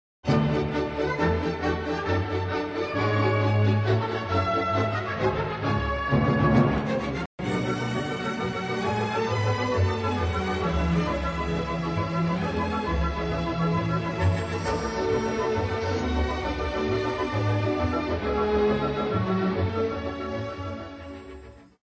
Orchestersuite in 3 Sätzen (leichte Sinfonik)